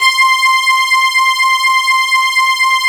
12 STRING C5.wav